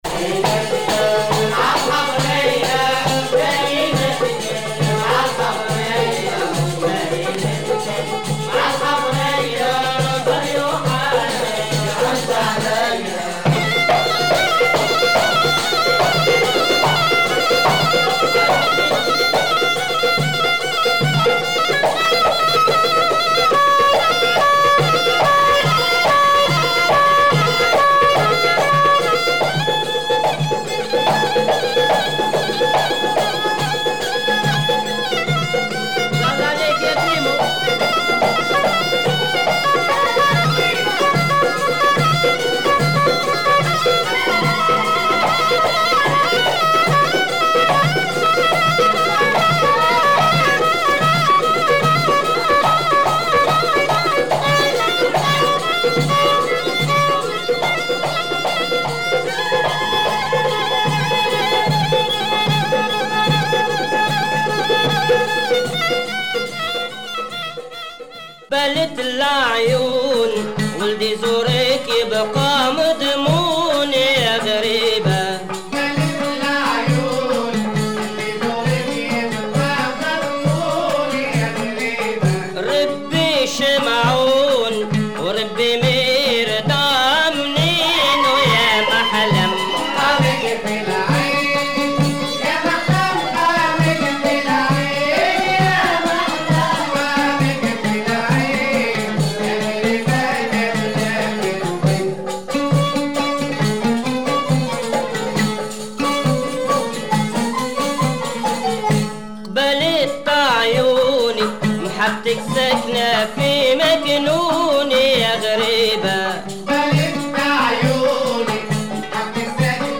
Deep Jewish Tunisian folk music